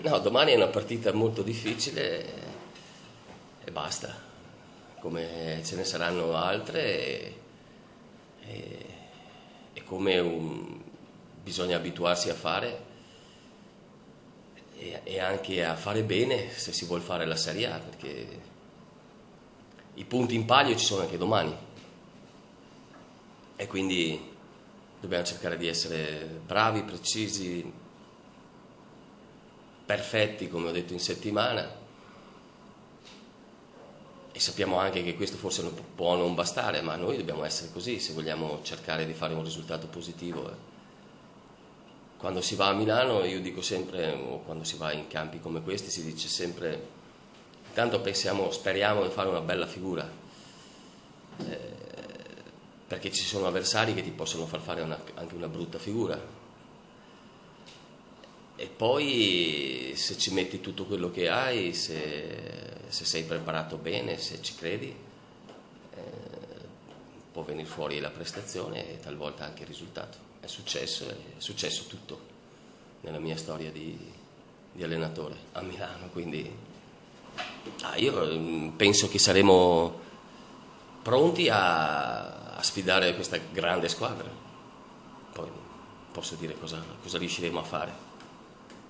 A lato l’audio della conferenza stampa di questa mattina a Collecchio del tecnico Francesco Guidolin